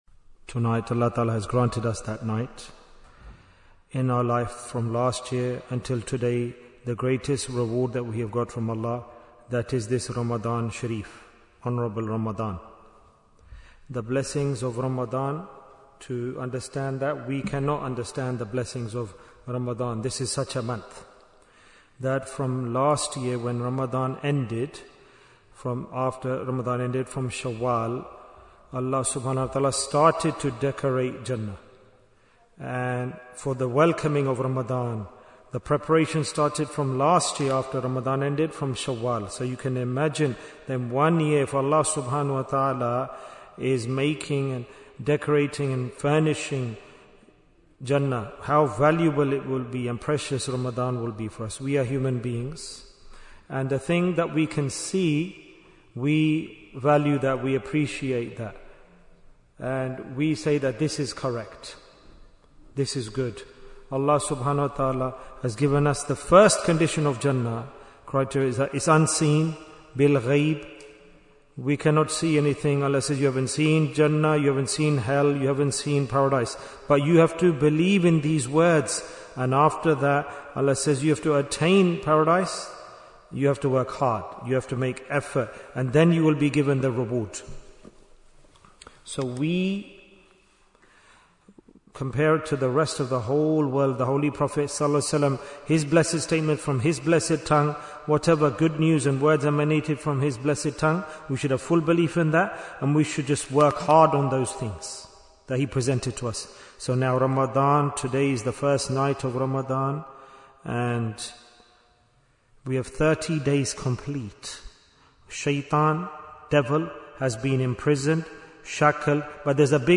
Jewels of Ramadhan 2026 - Episode 1 Bayan, 17 minutes17th February, 2026